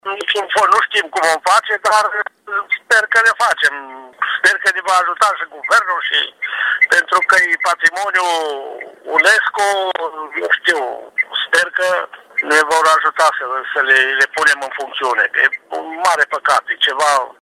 Primarului comunei, Ilie Imbrescu, a declarat pentru Radio Timișoara, că trei dintre morile de apă vor fi demolate, din cauza avariilor majore.